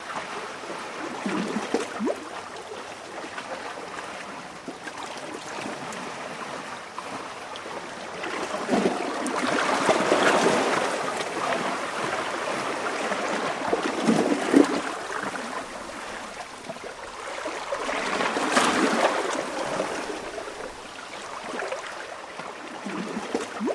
Water Laps Up Into Pier Or Dock With Some Waves